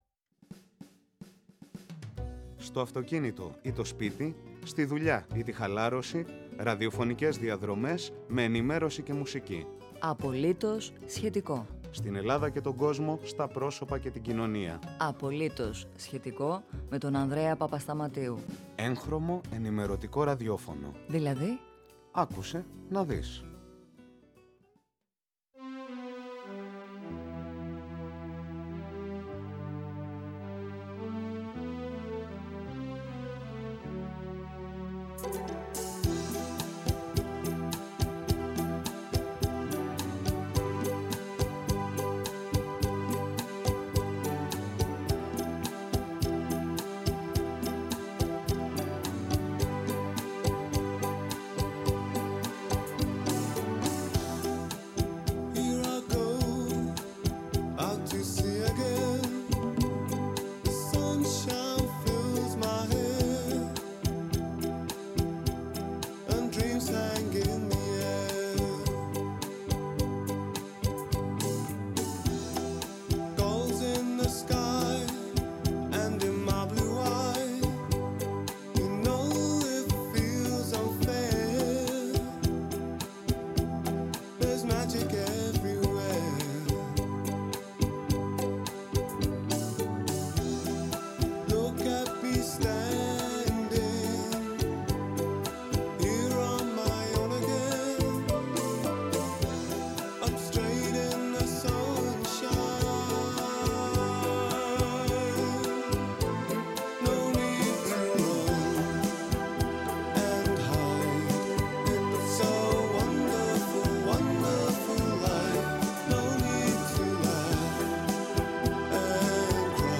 Απογευματινή ενημέρωση και ραδιόφωνο; «Απολύτως … σχετικό»!